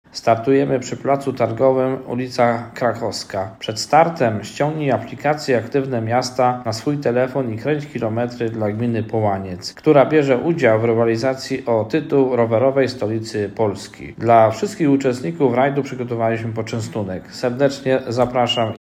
Do udziału w rajdzie zachęca burmistrz Jacek Nowak.